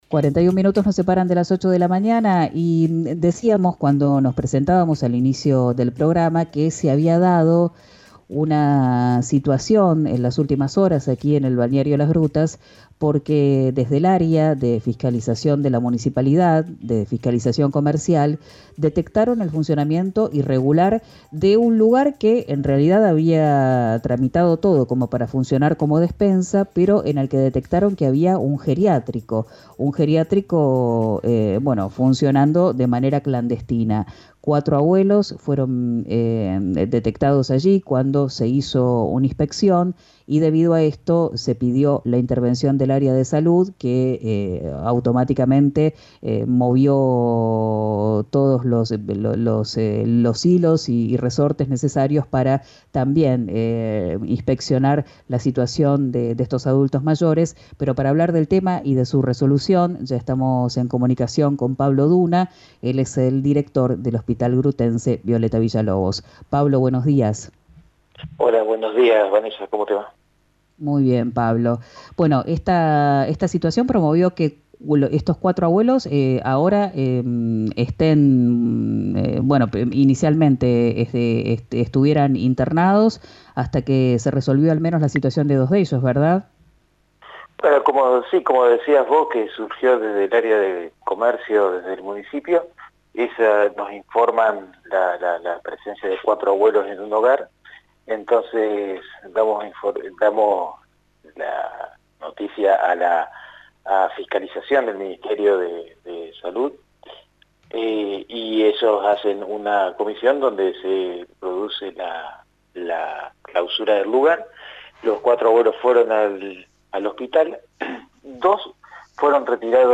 habló con «Quien dijo verano» por RÍO NEGRO RADIO sobre el procedimiento que determinó el hallazgo de una despensa donde funcionaba -irregularmente- un geriátrico.